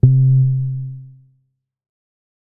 MoogSubDrop A.WAV